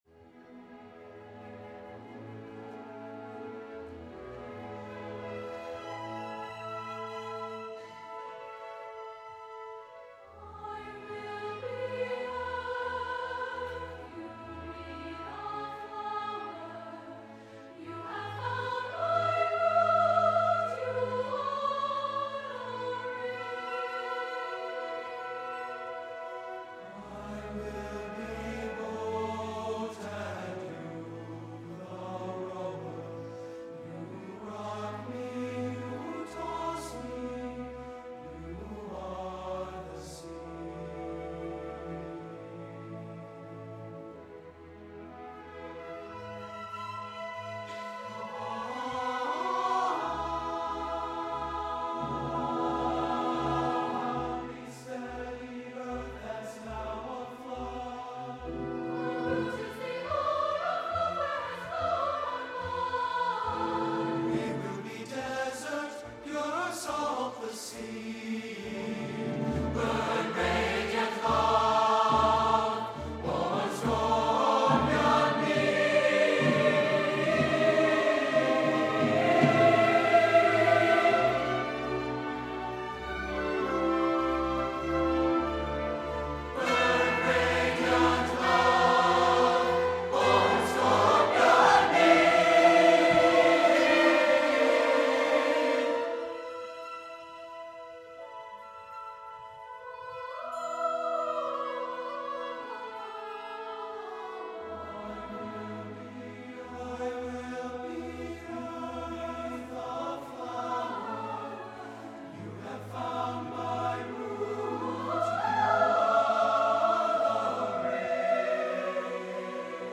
SATB and orchestra